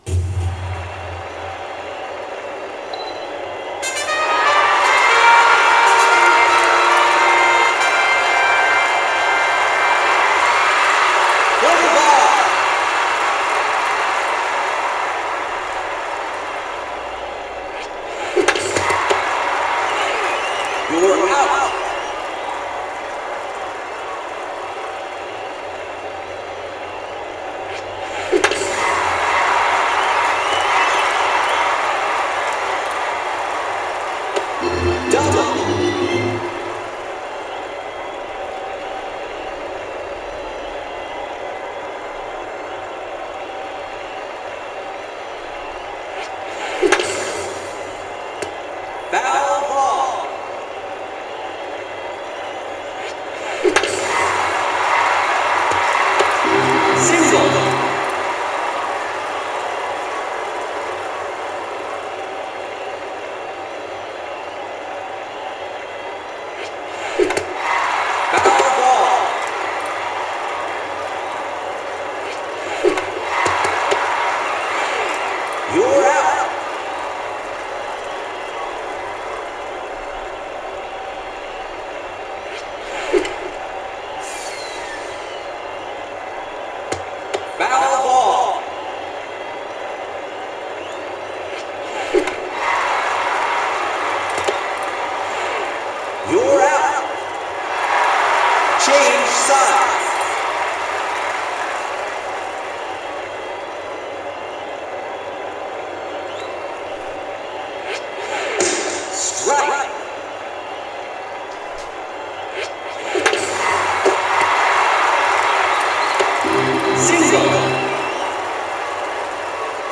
La palla emette un sibilo quando viene lanciata… 1 secondo o forse poco più da quando lo si sente e… paaaam!!! colpire!!!
Fate attenzione a quanto tempo trascorre tra il sibilo della palla che viene lanciata e la battuta.